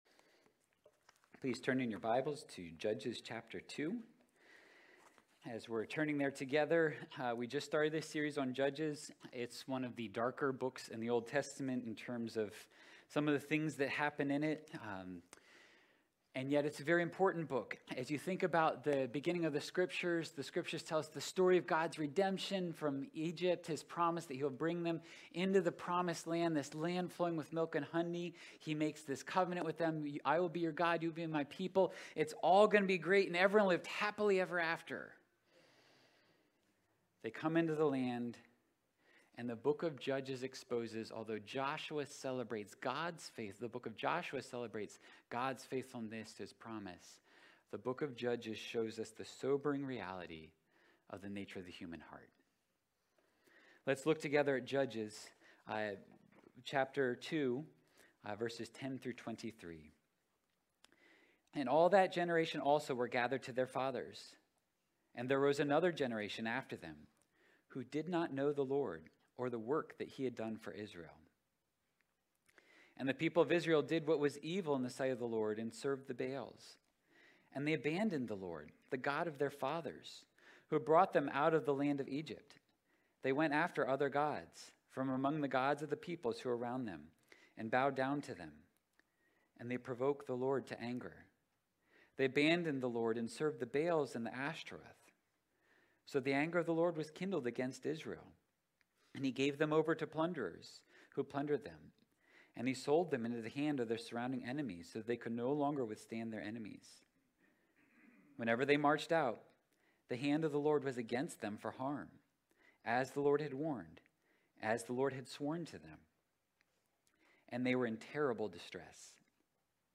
Sunday Worship – September 18 of 2022 – A Downward Spiral